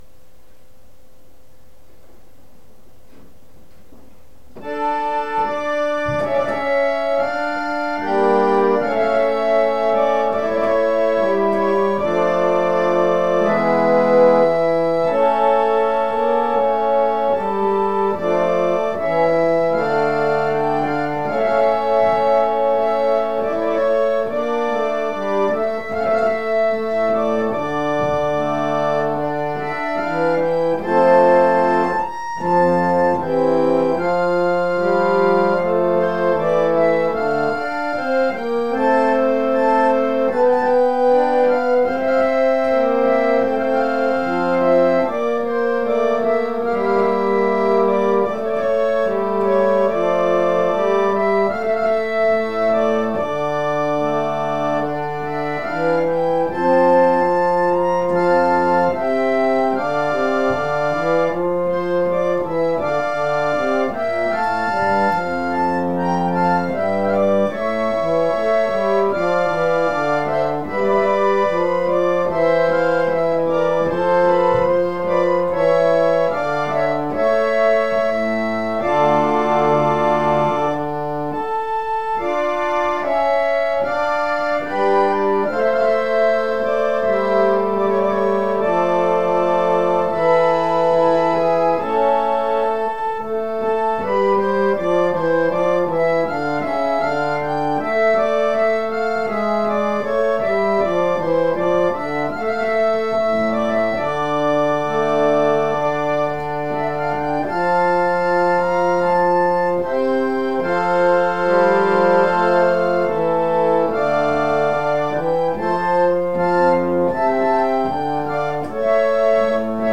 黒人霊歌